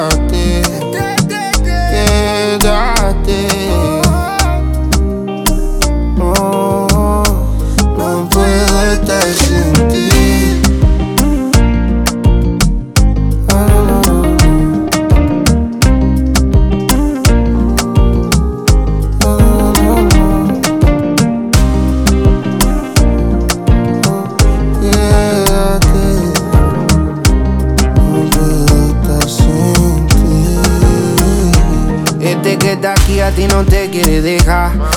Urbano latino Latin African Afro-Pop
Жанр: Поп музыка / Латино